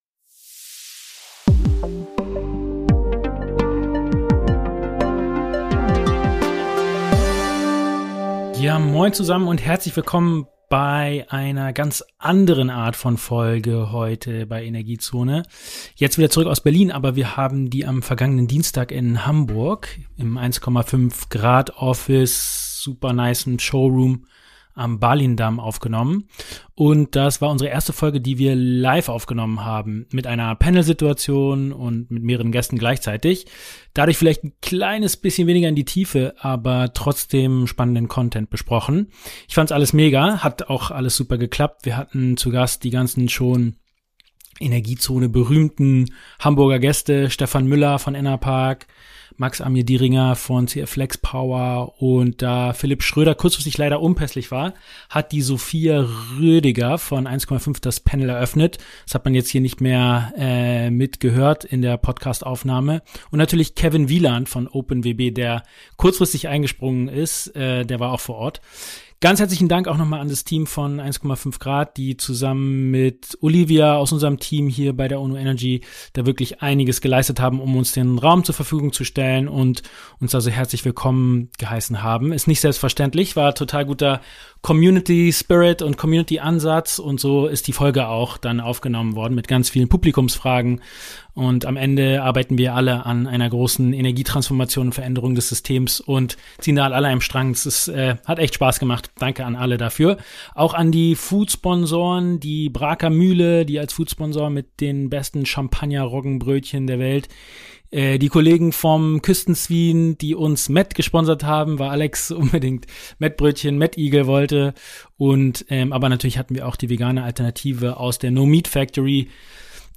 E#46 Live Podcastaufnahme vom Panel beim ersten Energiezone - Energiefrühstück ~ ENERGIEZONE Podcast